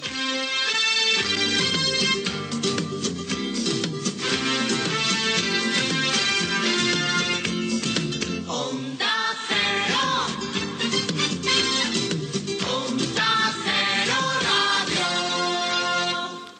Un dels primers indicatius de l'emissora